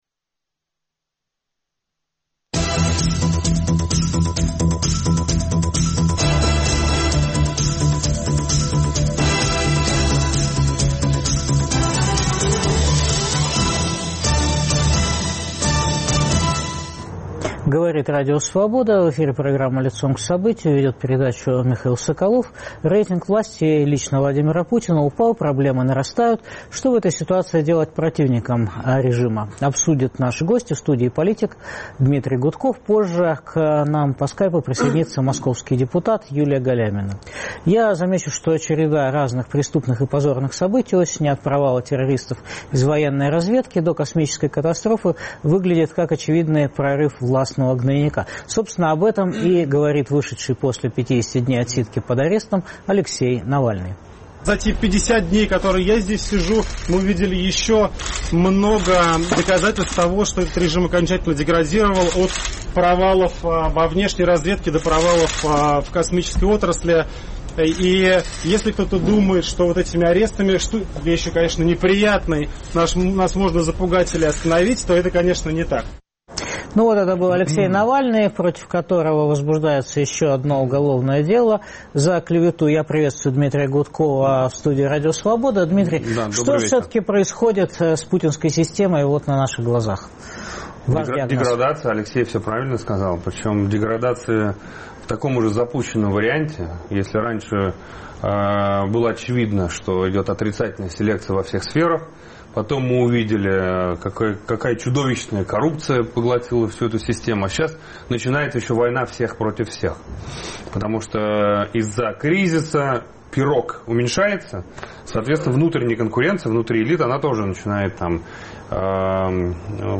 Что делать противникам режима обсудят Дмитрии Гудков, Юлия Галямина. В эфире интервью Сергея Пархоменко